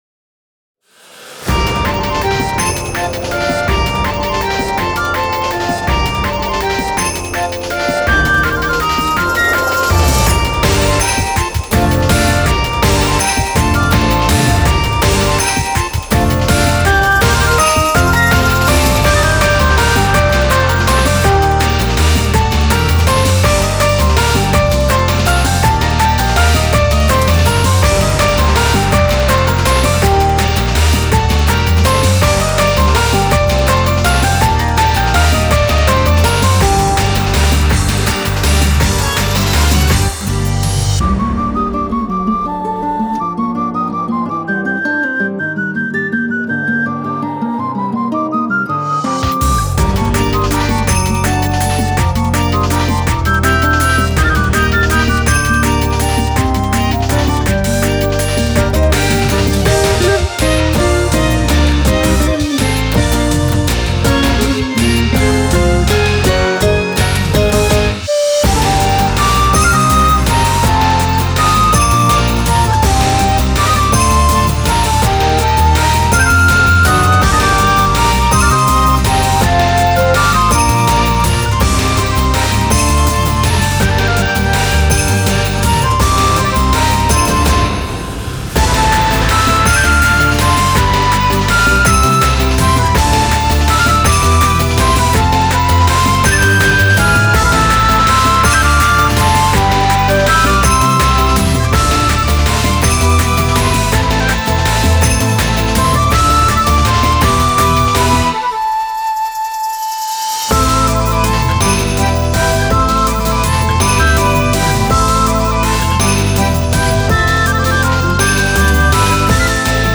BPM164
Audio QualityPerfect (High Quality)
Challenge 4: It's a 3/4 song